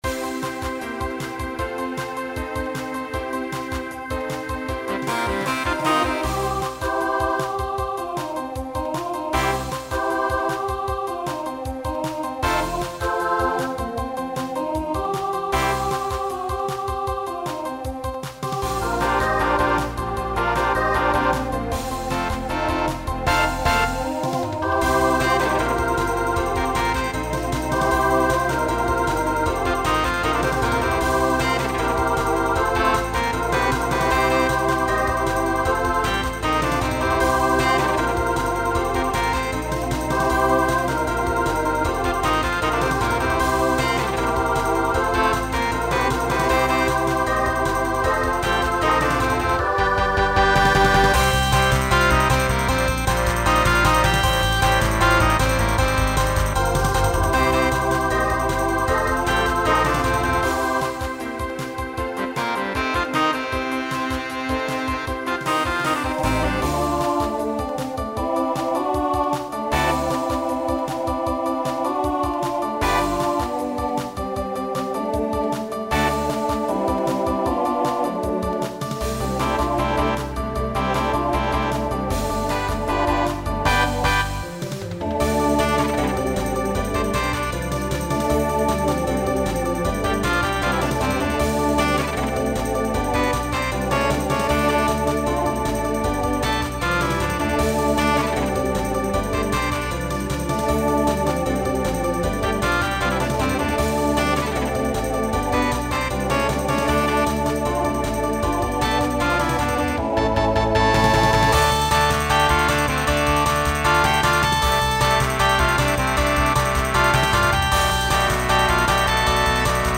Starts SSA, then TTB, then SATB.
Genre Rock
Transition Voicing Mixed